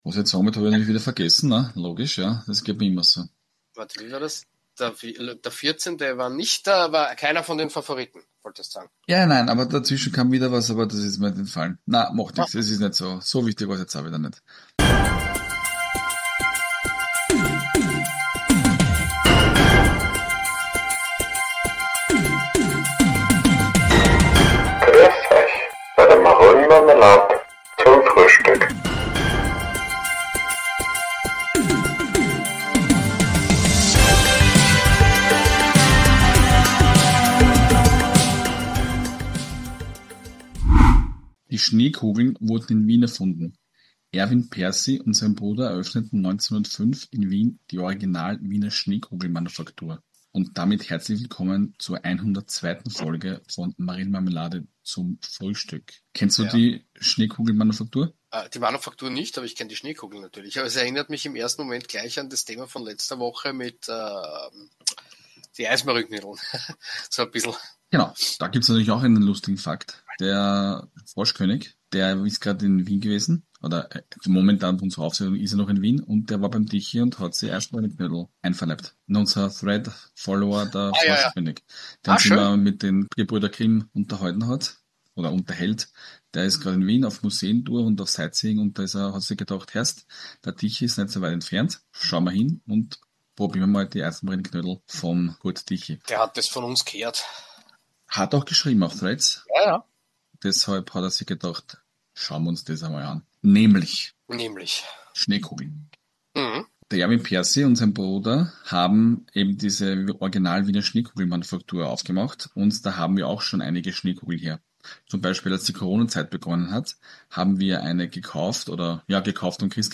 1 Bibiana Steinhaus-Webb - Wir brauchen Frauen im Fußball 56:54 Play Pause 5h ago 56:54 Play Pause Später Spielen Später Spielen Listen Gefällt mir Geliked 56:54 Die vierfache Weltschiedsrichterin spricht mit Arnd Zeigler u.a. über ihren beruflichen Werdegang in einer Männerdomäne, natürliche Autorität, gute Abseitsentscheidungen und die Rolle des VAR.